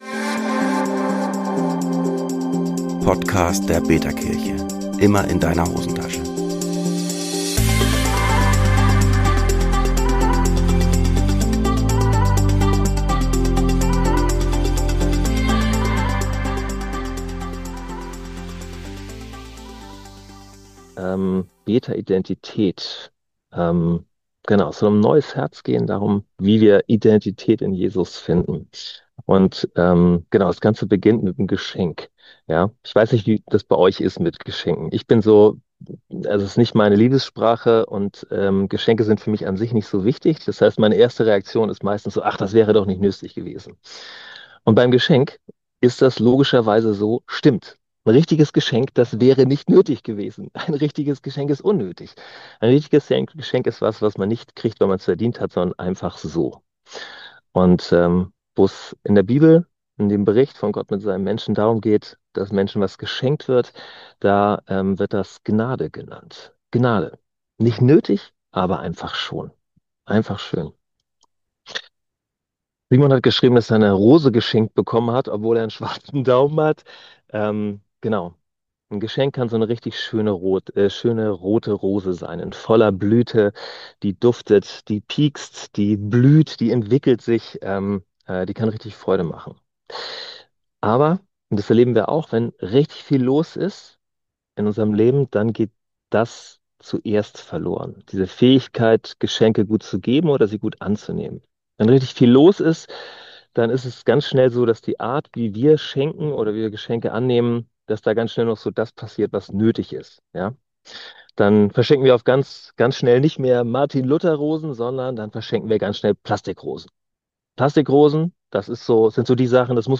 Gottesdienst | betaIdentität - Wer bin ich wirklich – in Christus?